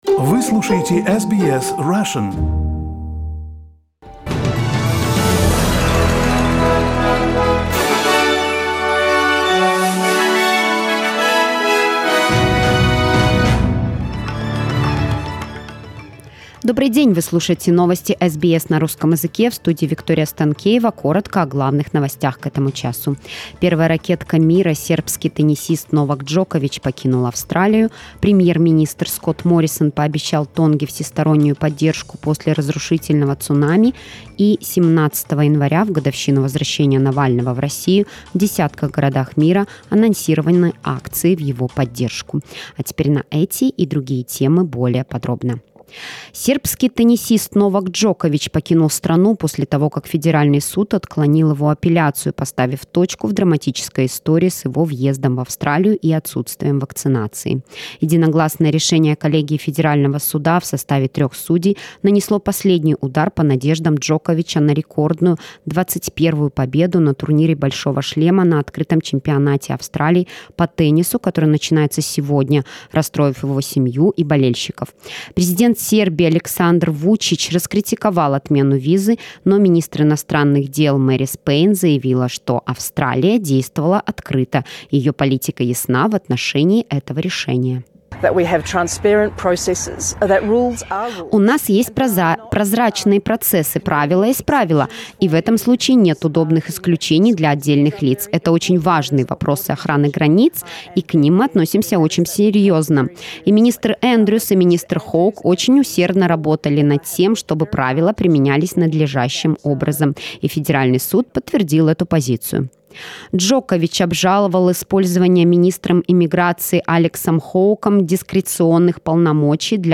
SBS news in Russian - 17.01